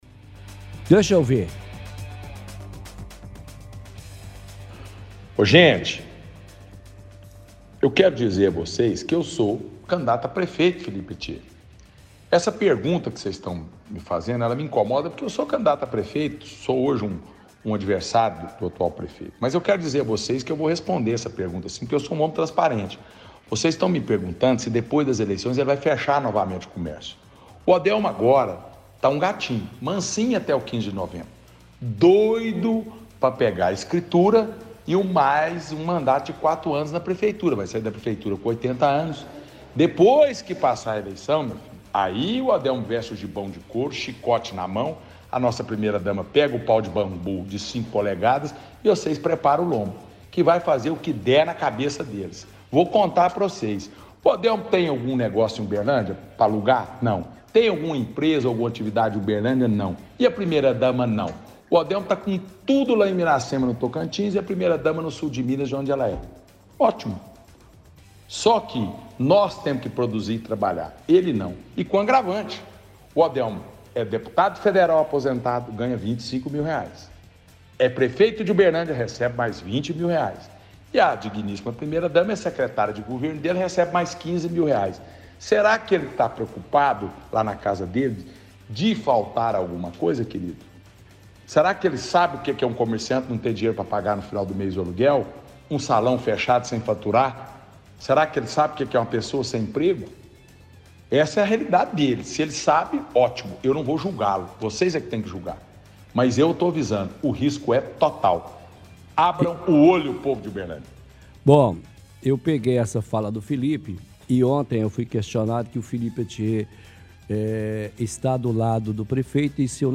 – Transmissão de áudio completo do Felipe Attiê respondendo se o prefeito iria fechar o comércio após as eleições.
Radialista responde que não sabe.